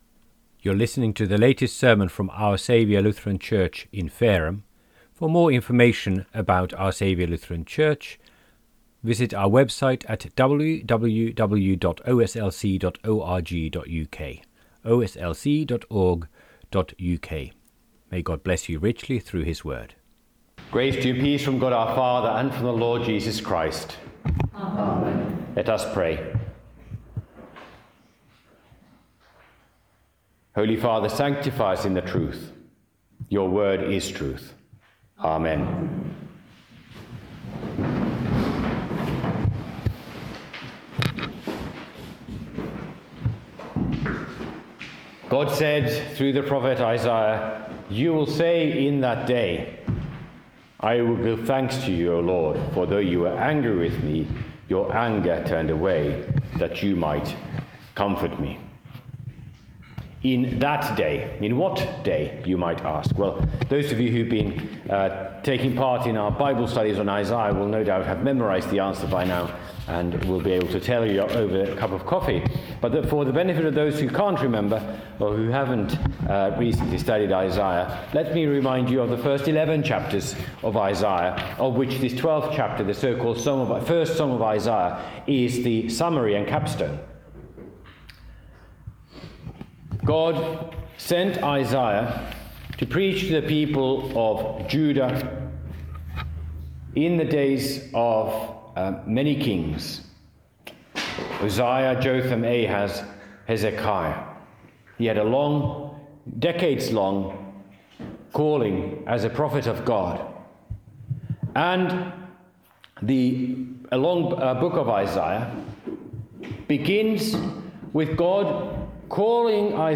Sermons and Bible studies from Our Saviour Lutheran Church, Fareham, UK